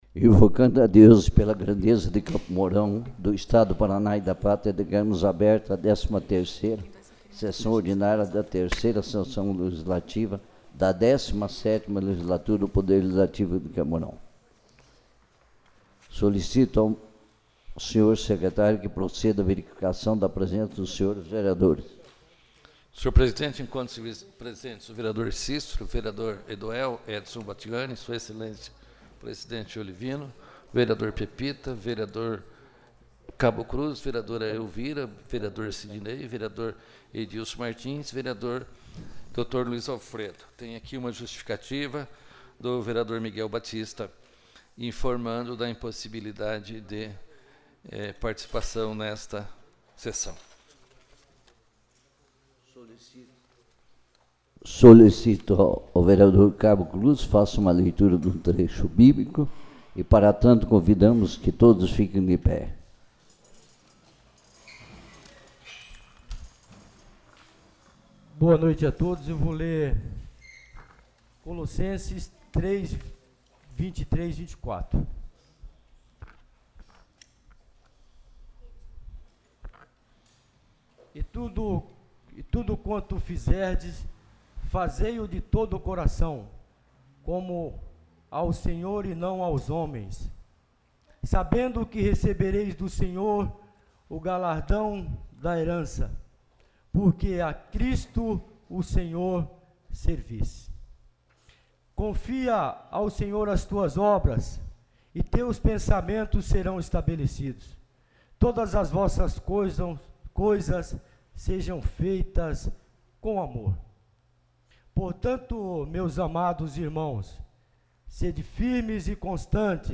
13ª Sessão Ordinária